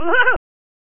Dungeon Master and Chaos Strikes Back (Amiga) - Attack (Giggler)
Game,Dungeon_Master_and_Chaos_Strikes_Back,Amiga,Attack_(Giggler),Sound.mp3